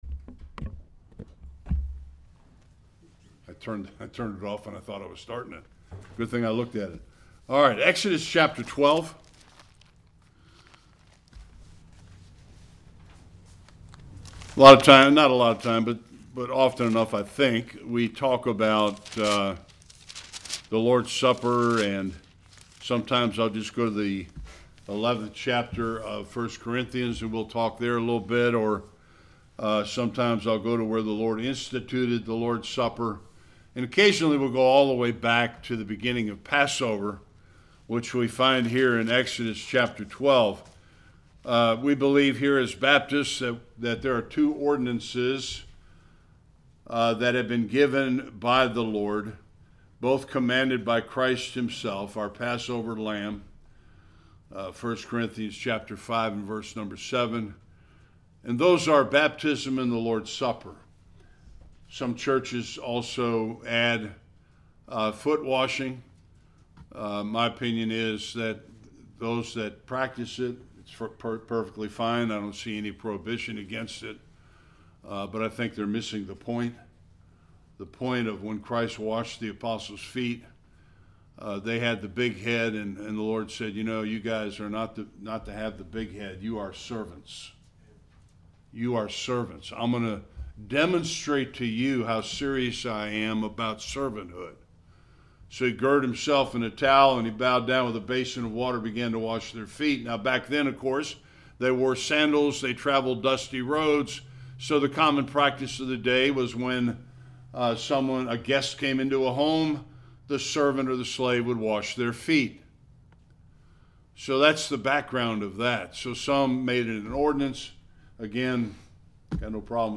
Various Passages Service Type: Sunday Worship Why do we take communion?